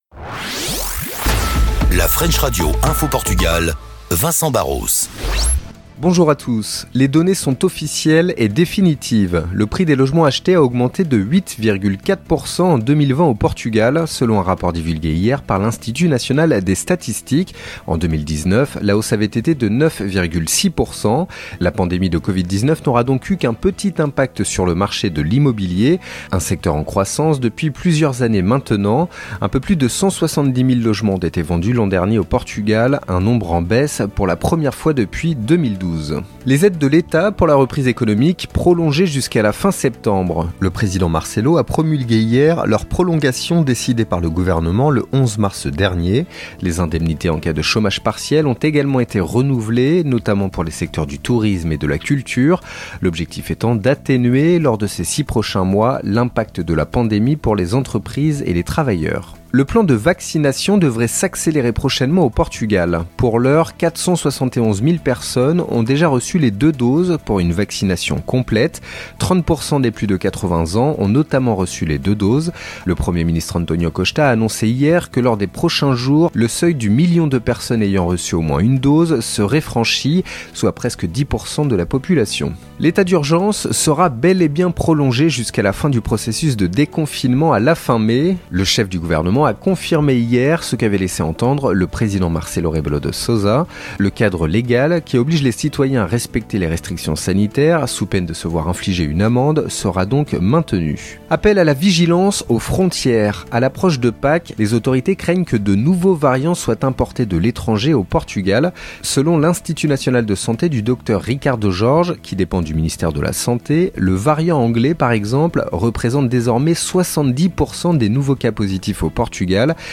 3. Flash Info - Portugal